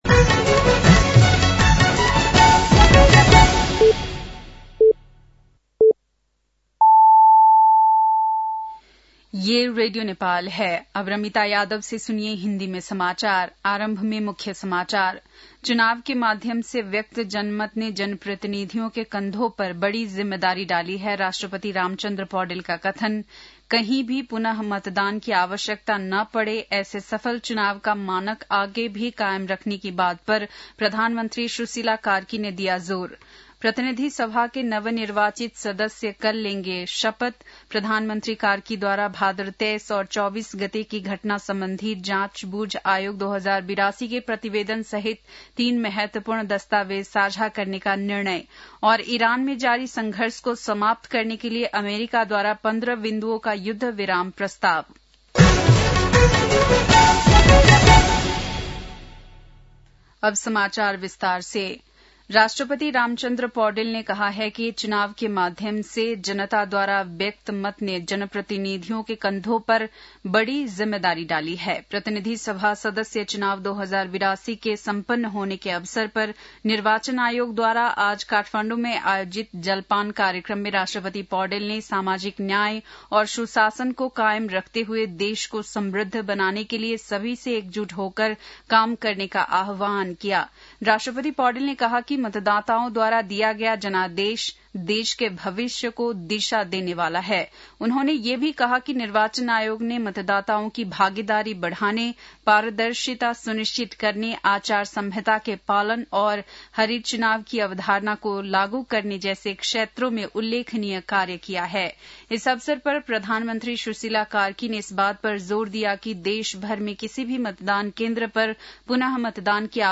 बेलुकी १० बजेको हिन्दी समाचार : ११ चैत , २०८२